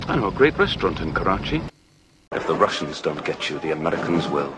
And he’s quite capable of using both variants in the same sentence:
dalton_approx_tap.mp3